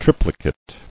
[trip·li·cate]